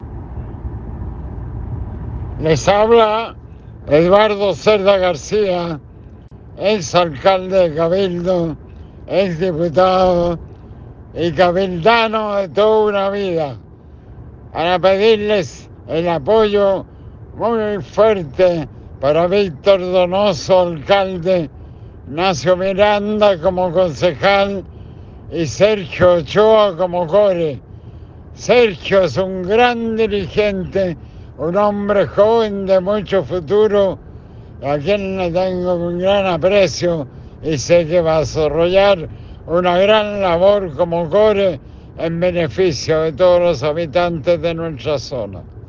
Una grata visita de dos grandes amigos nos visitaron en el nuevo estudio